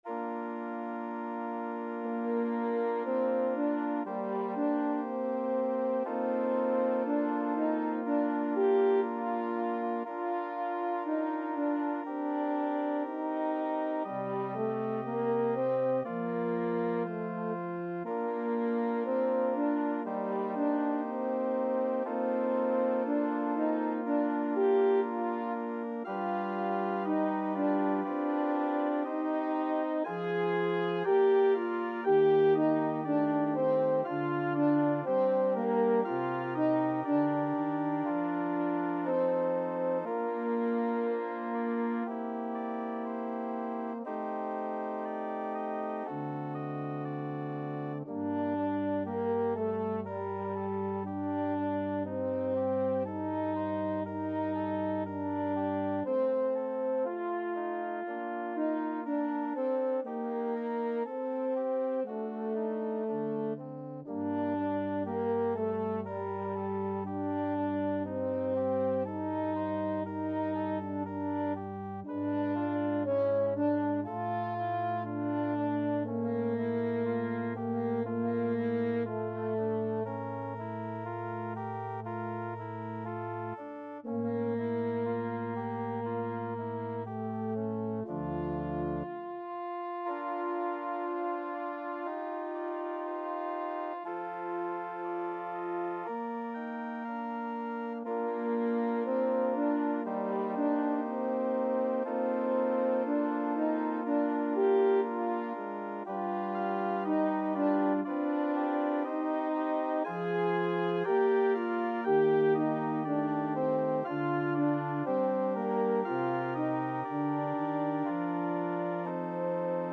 French Horn
Bb major (Sounding Pitch) F major (French Horn in F) (View more Bb major Music for French Horn )
~ = 100 Andantino sempre legato =60 (View more music marked Andantino)
Classical (View more Classical French Horn Music)